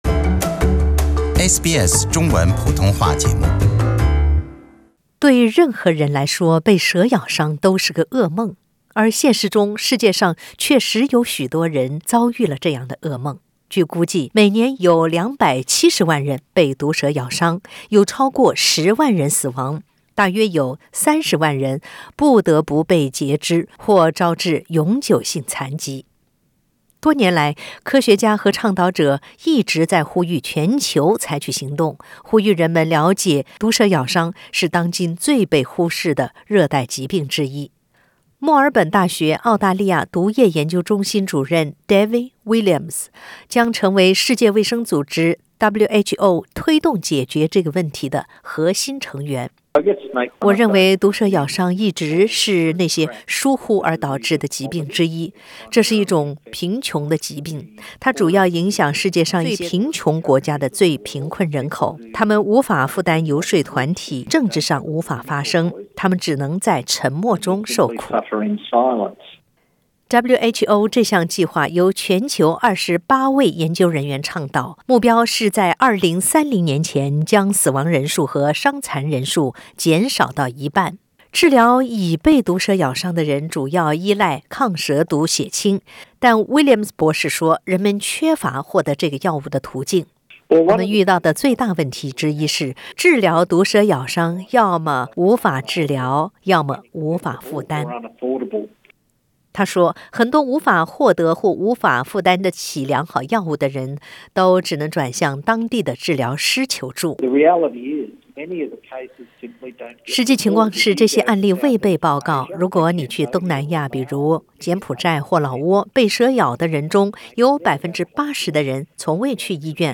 SBS Mandarin